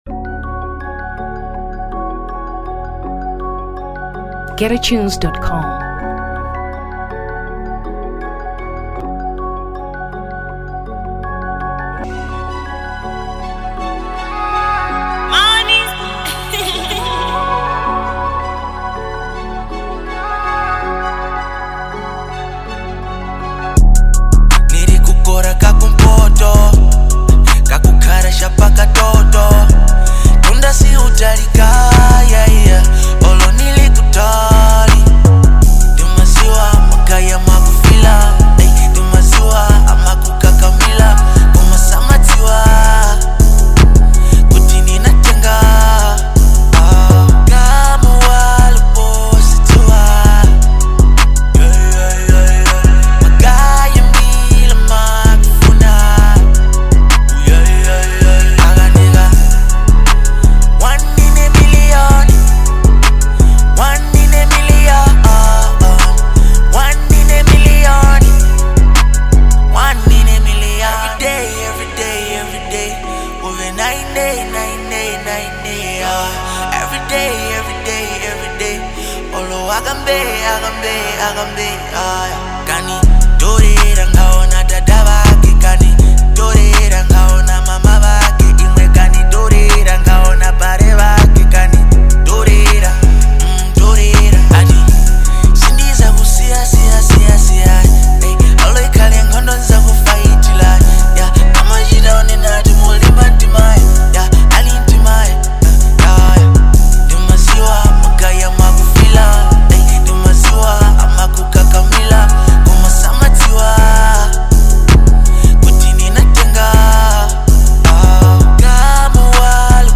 Trap 2023 Malawi